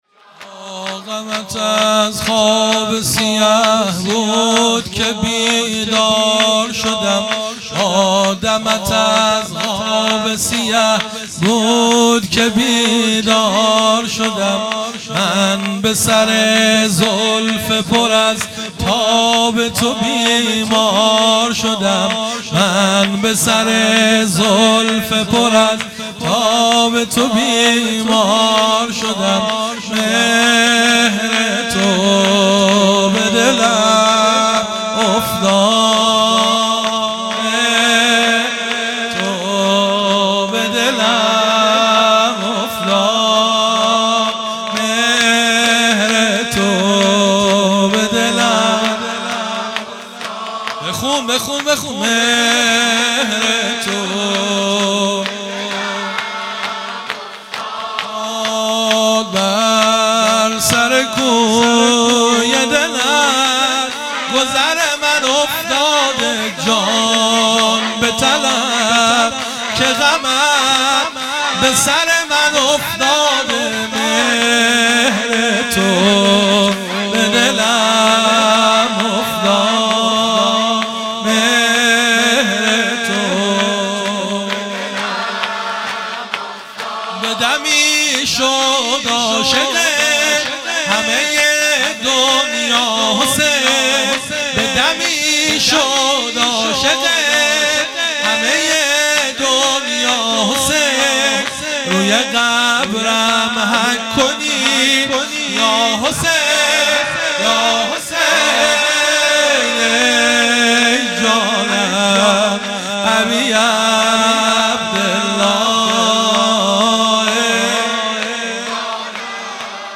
هیئت مکتب الزهرا(س)دارالعباده یزد
شور ۲
فاطمیه 1401_شب اول